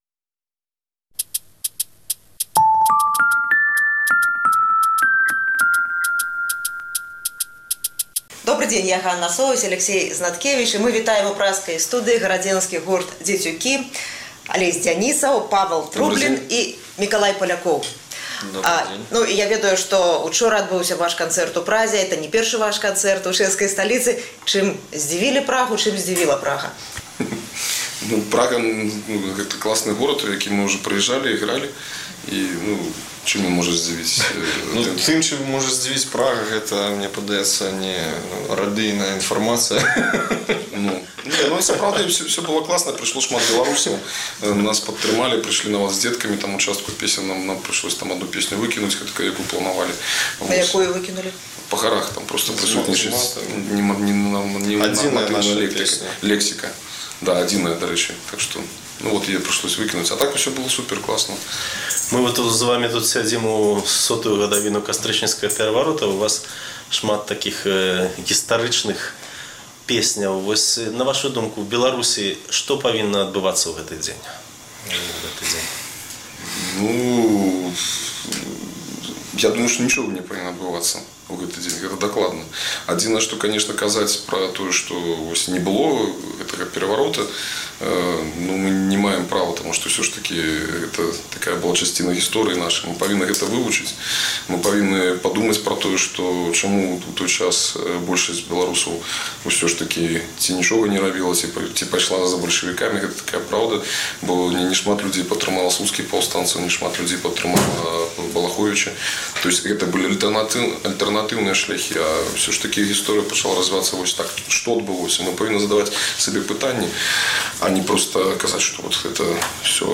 Удзельнікі гарадзенскага гурту «Дзецюкі» наведалі праскую студыю Свабоды
прасьпявалі некалькі песень